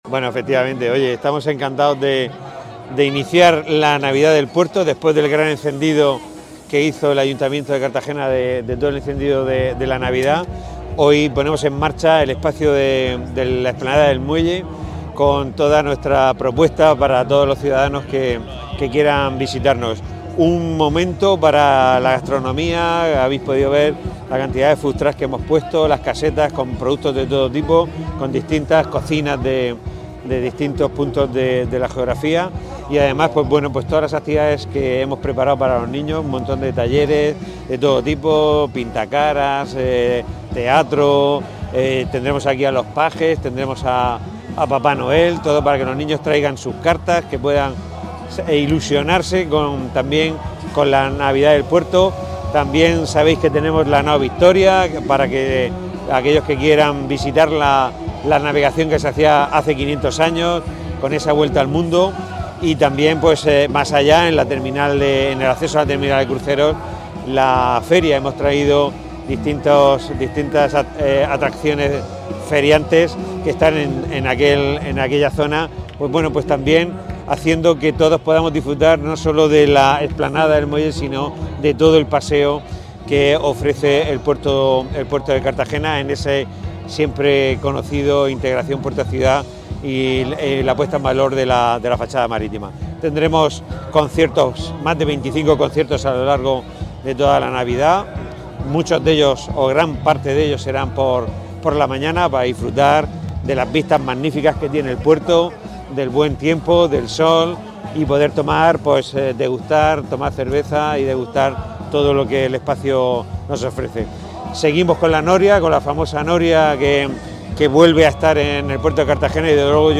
Enlace a Declaraciones del Presidente de la Autoridad Portuaria y el Concejal de Cultura por la inauguración espacio navideño explanada del puerto